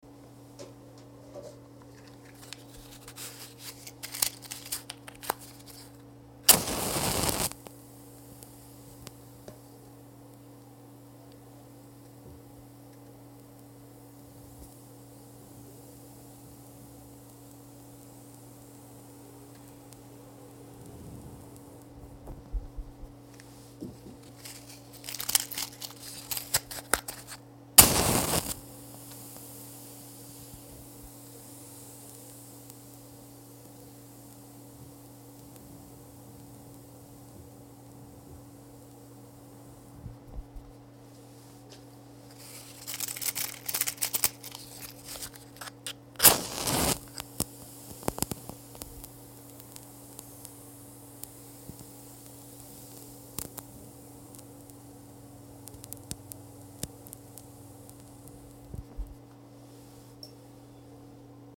Звуки спичек, зажигалок
Шорох зажигающейся спички